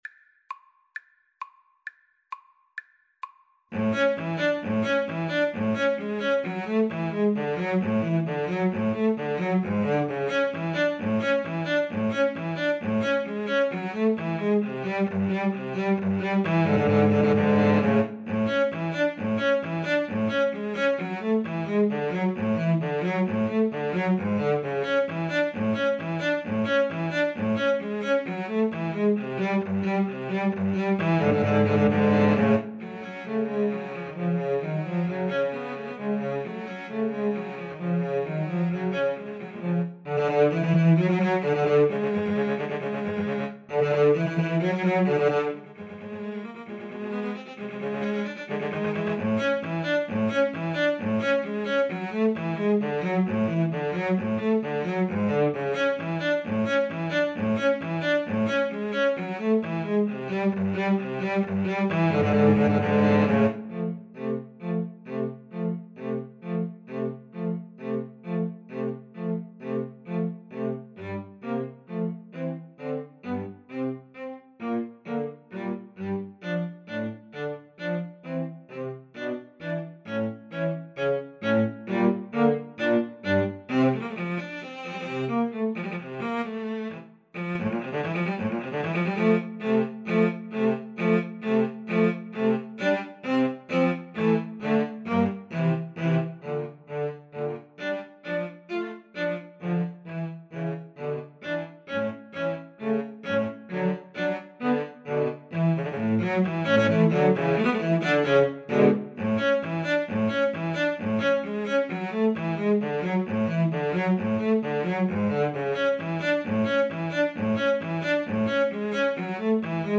Cello Trio  (View more Intermediate Cello Trio Music)
Classical (View more Classical Cello Trio Music)